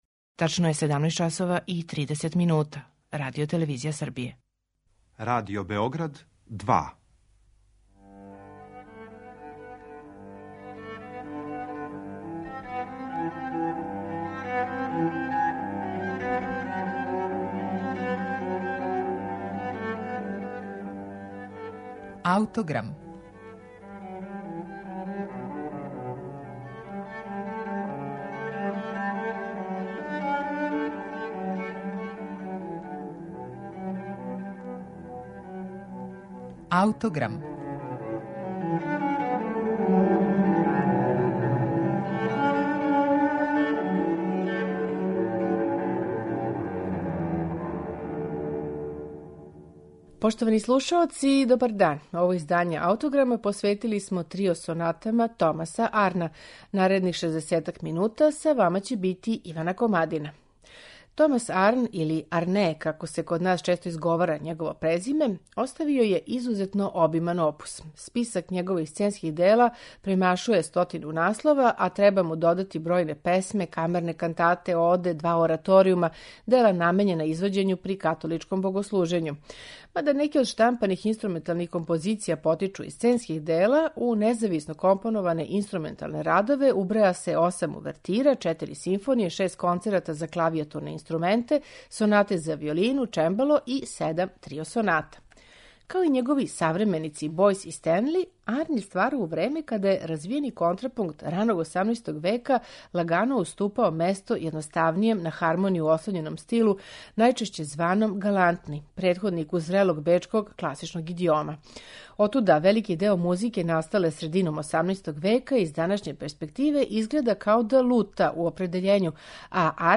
Слушаћете трио сонате Томаса Арна
Управо ову збирку, објављену 1757. године, представићемо у данашњем Аутограму. Арнове трио сонате слушаћете у интерпретацији чланова ансамбла Collegium Musicum 90, под управом Сајмона Стендиџа.